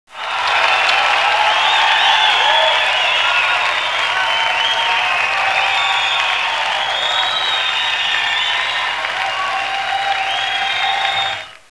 • מחיאות_כפים.wav